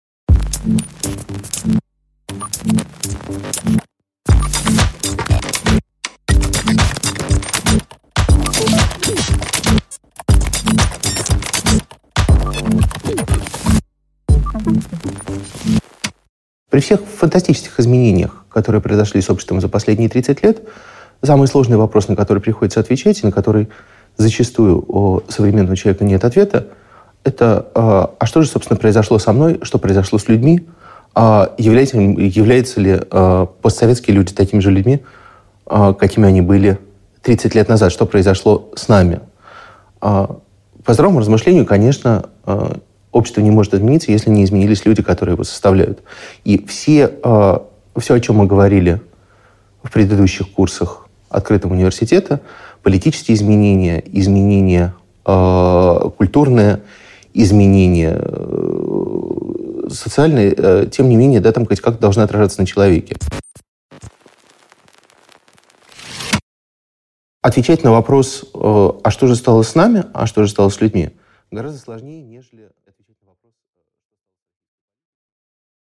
Аудиокнига Другая история: предмет и метод исследования | Библиотека аудиокниг